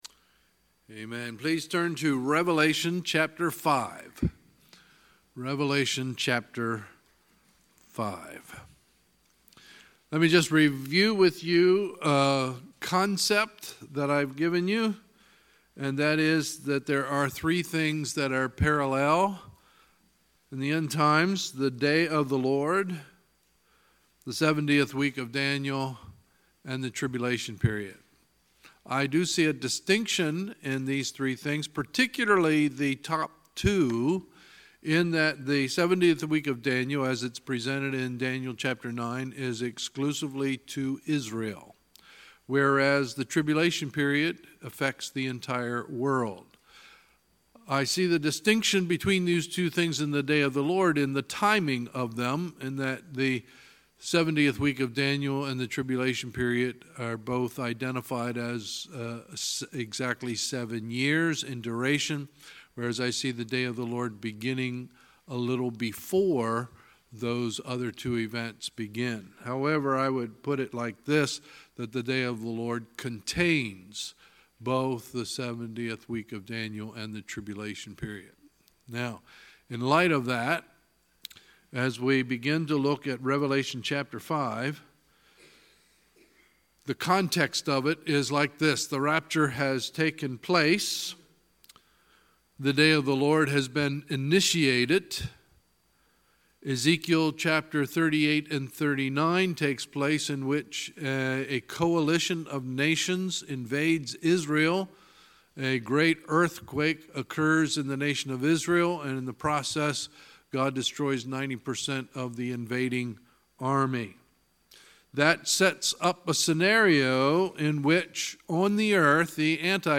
Sunday, November 4, 2018 – Sunday Evening Service